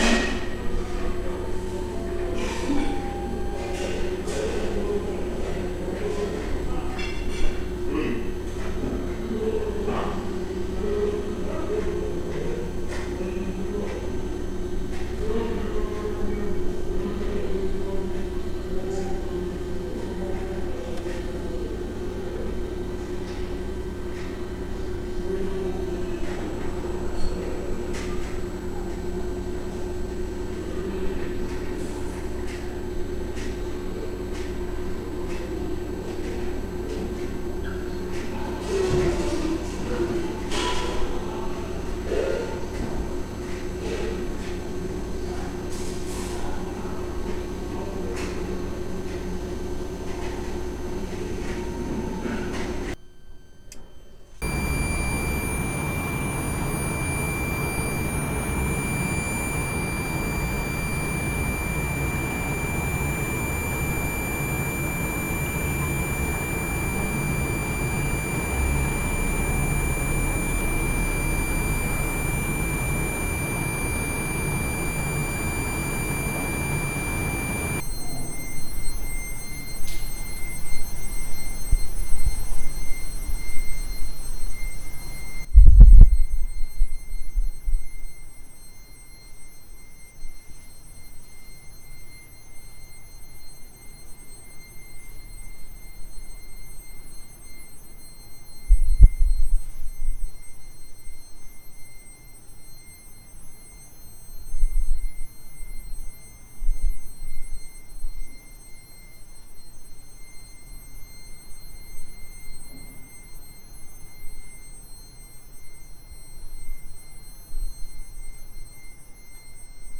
The recording device I used here is still a handheld device but slight more sophisticated.